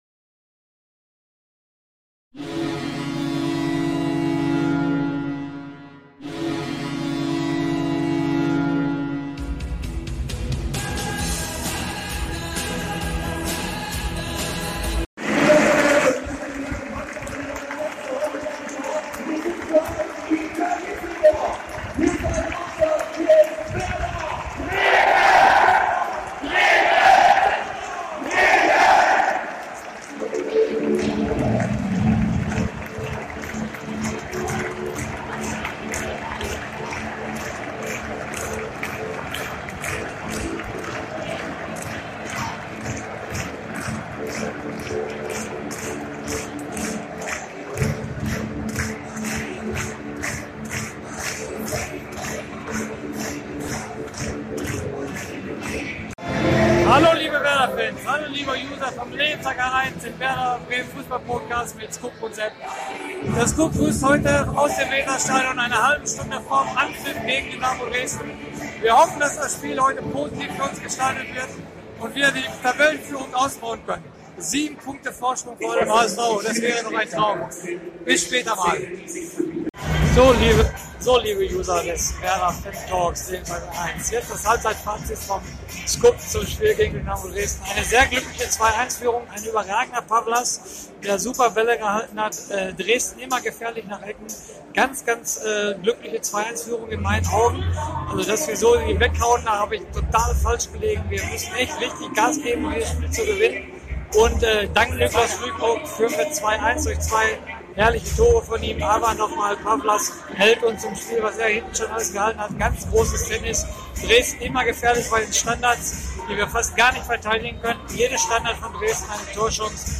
Werder Bremen vs. Dynamo Dresden - Impressionen vom Spiel
werder-bremen-vs-dynamo-dresden-impressionen-vom-spiel.mp3